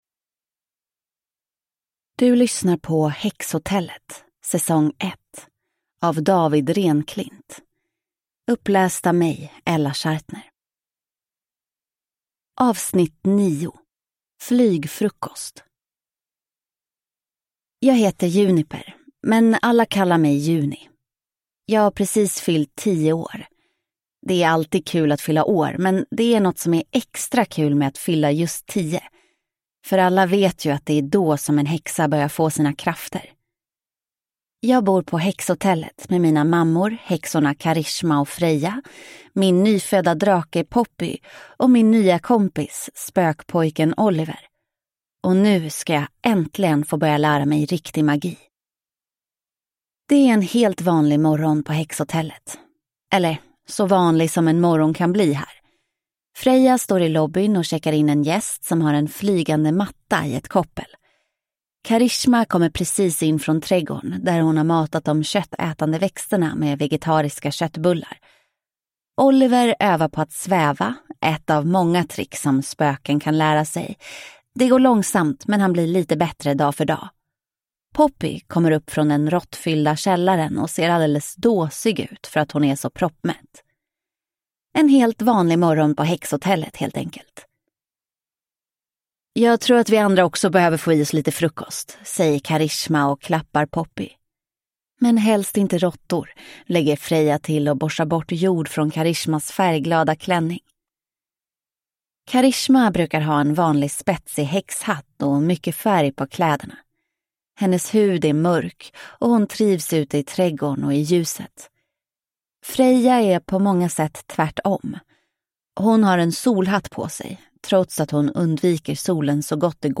Flygfrukost (S1E9 Häxhotellet) – Ljudbok